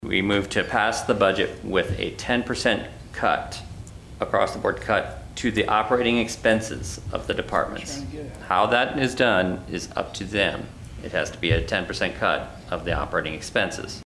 During Tuesday’s county commission meeting, Commissioner Ryan Heine, stated that how they cut ten percent is up to each department.